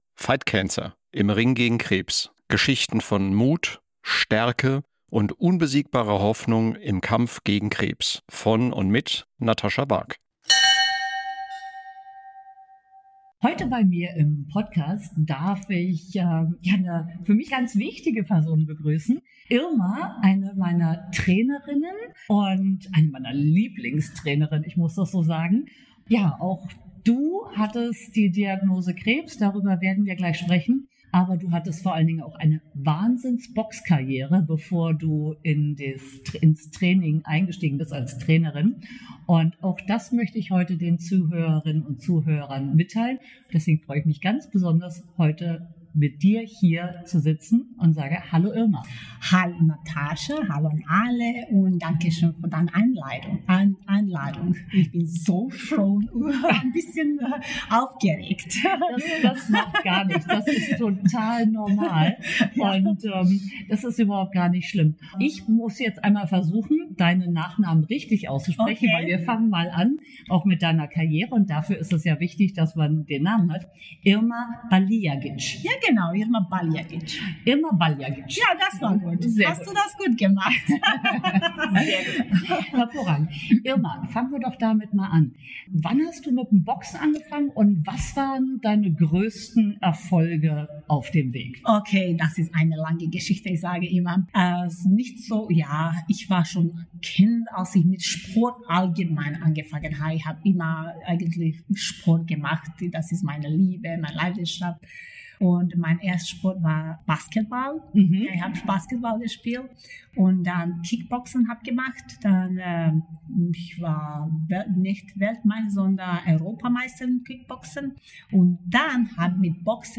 PS: Die Aufnahme erfolgte nach einem Training und es hallt etwas mehr als sonst.